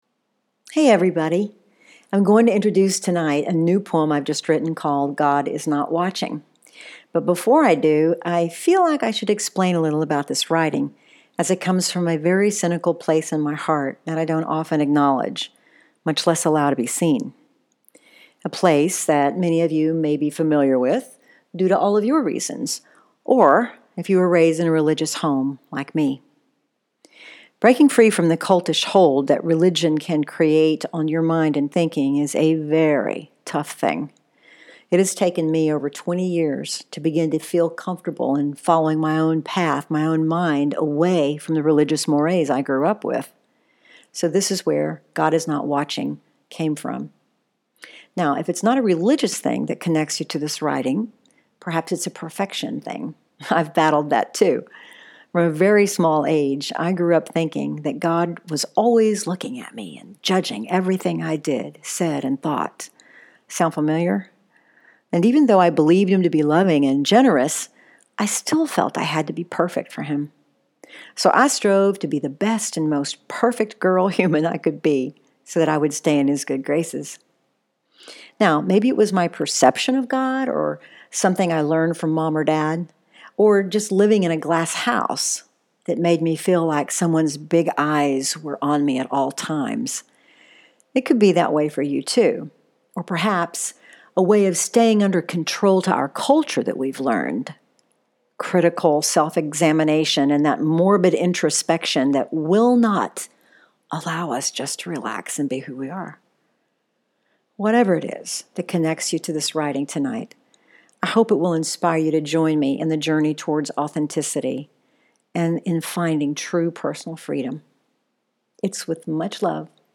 Poetry, Religiousity, Soul searching